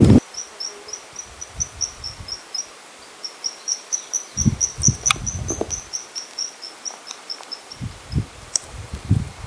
Andean Swift (Aeronautes andecolus)
Life Stage: Adult
Province / Department: Mendoza
Location or protected area: Quebrada de la virgen
Condition: Wild
Certainty: Observed, Recorded vocal